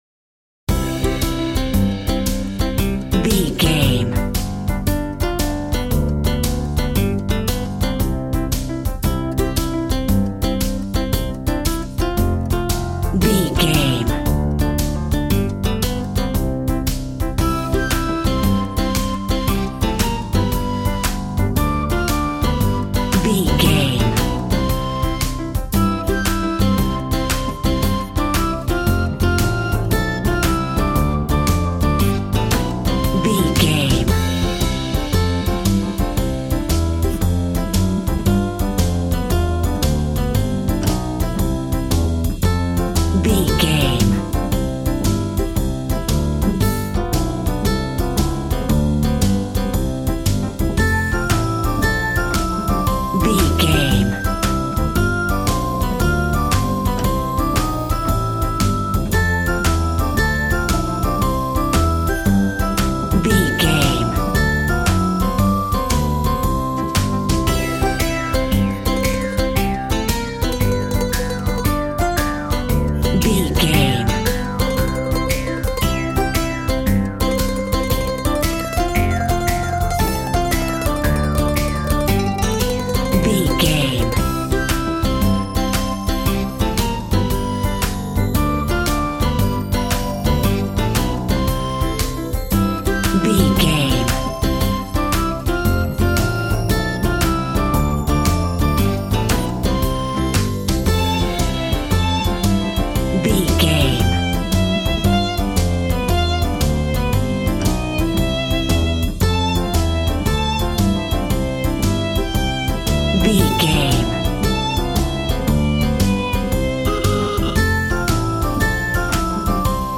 Ionian/Major
cheerful/happy
electric piano
electric guitar
drum machine